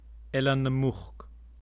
Eilean Earraid Pronunciation [ˈelan ˈɛrˠɪtʲ]